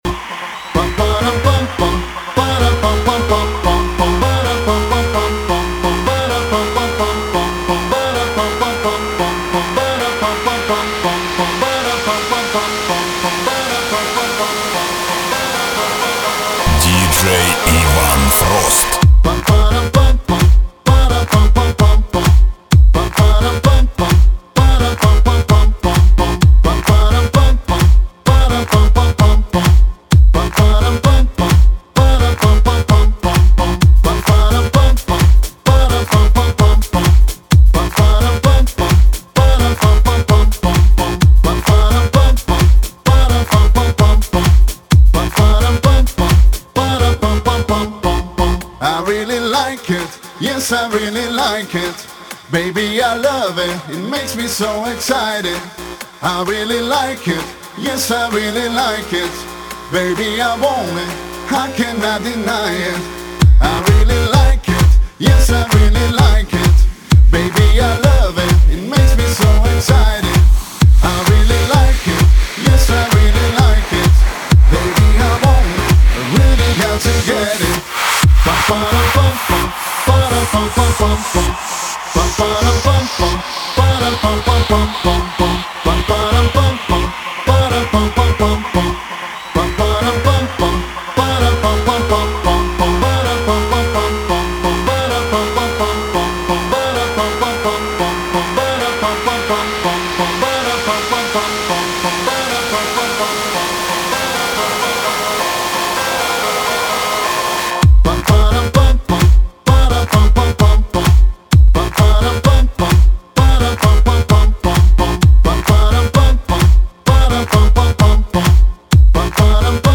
Категория: Club Music - Клубная музыка